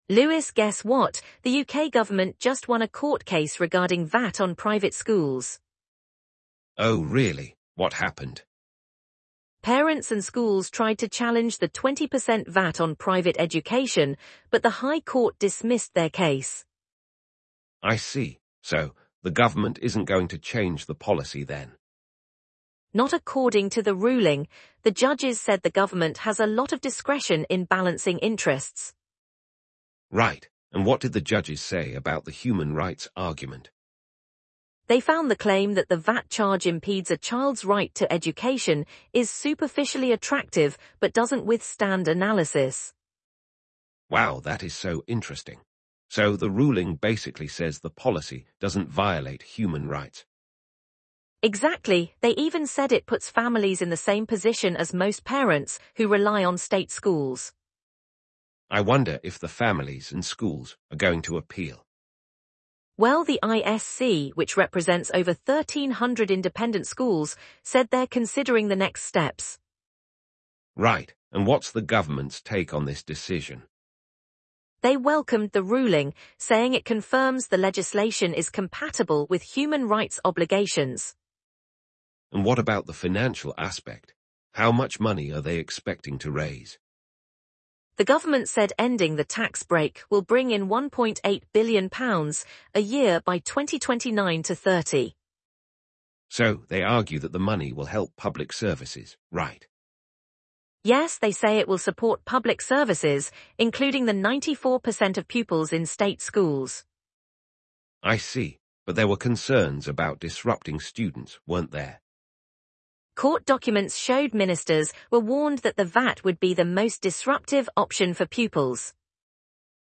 UK morning business news